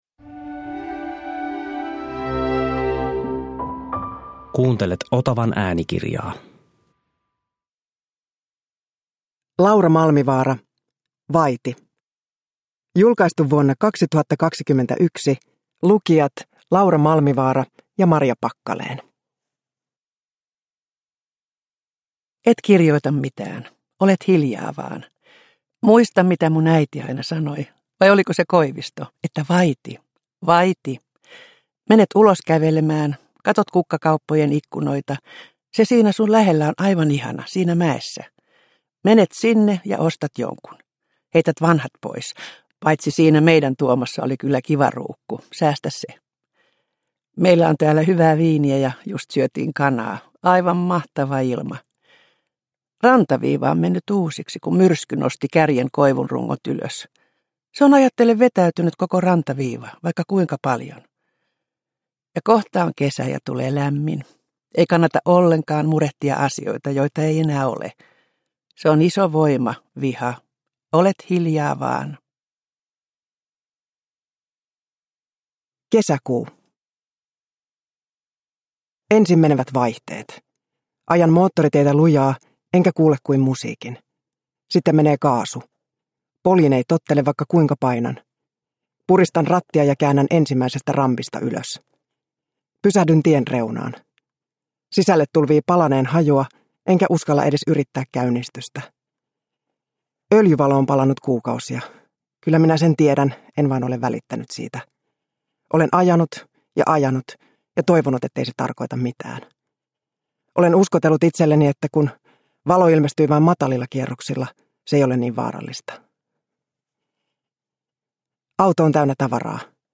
Vaiti – Ljudbok – Laddas ner
Uppläsare: Laura Malmivaara, Marja Packalén